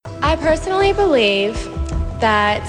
Category: Radio   Right: Personal
Tags: radio internet call 402 teh